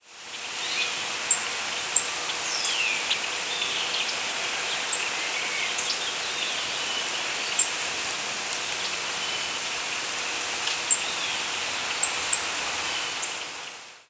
Brown Creeper Certhia americana
Foraging bird creeping around a tree.
Similar species Shorter and softer than chickadees or Golden-crowned Kinglet.